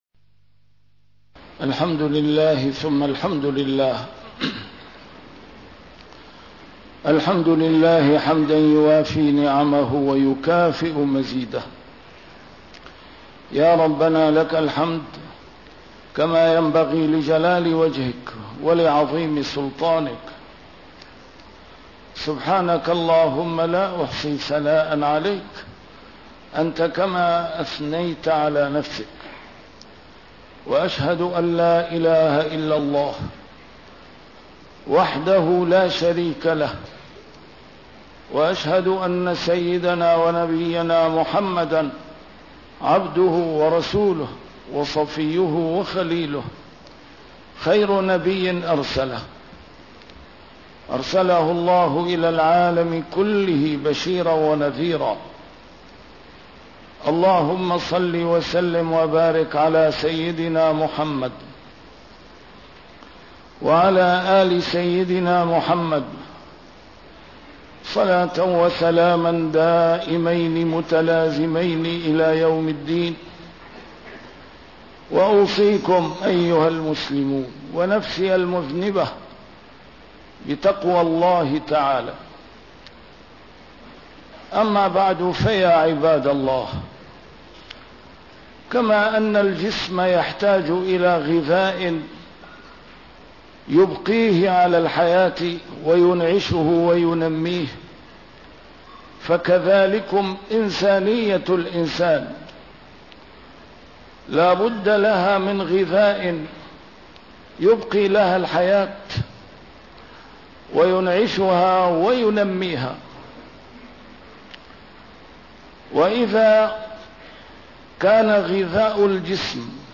A MARTYR SCHOLAR: IMAM MUHAMMAD SAEED RAMADAN AL-BOUTI - الخطب - موقف الإسلام والعالم الإسلامي من العمل الإجرامي الذي وقع في أمريكا